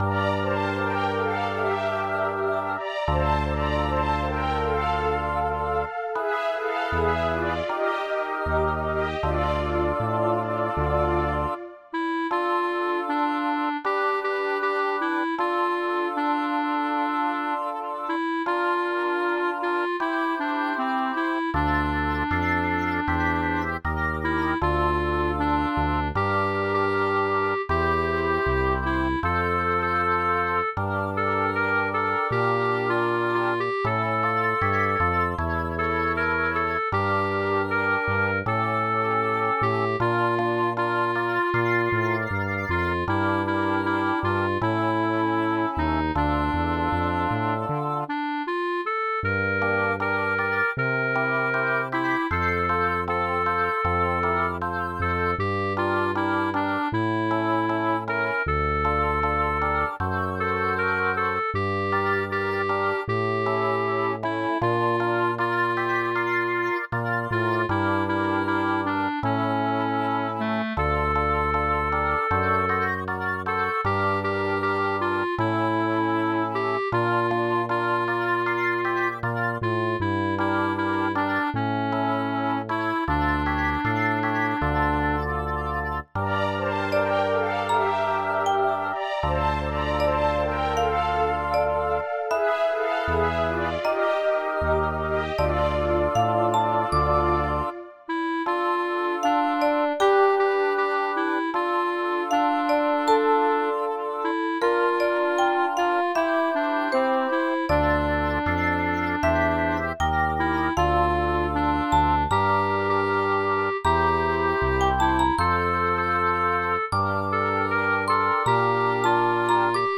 12 Чудова пісня! 16 І слова, і музика - супер!39 give_rose hi
Дуже лірично вийшло!